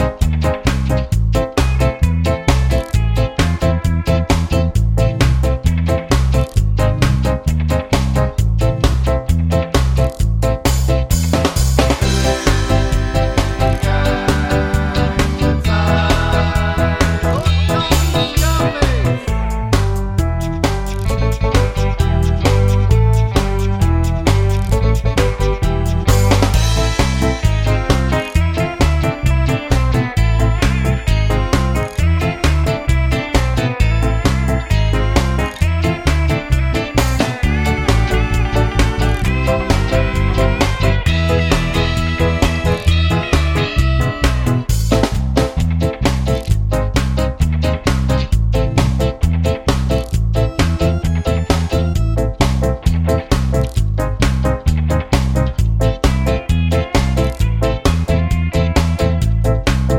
no spoken bits Ska 2:44 Buy £1.50